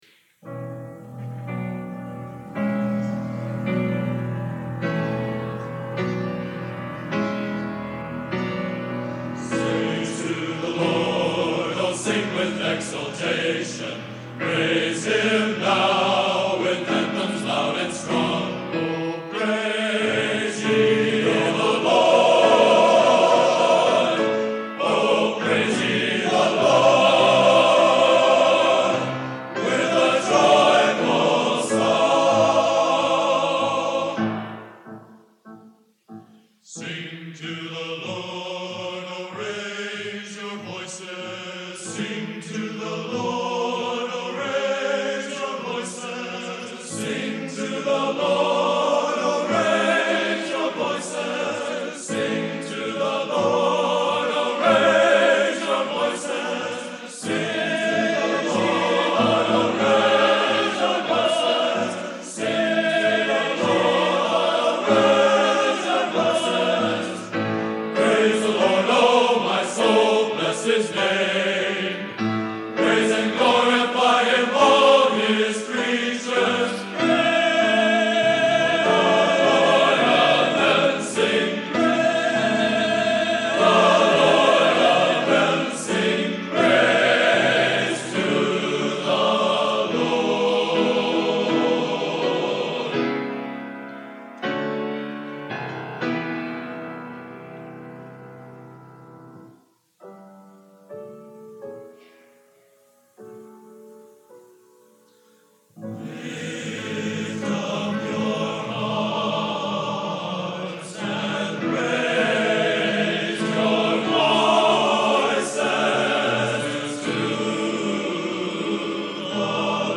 Collection: Broadway Methodist, 1980
Genre: Modern Sacred | Type: